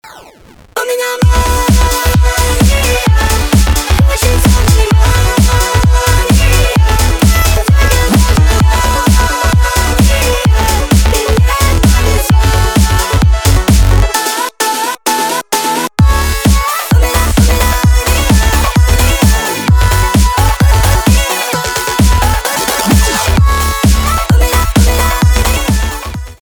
русский рэп
электроника
басы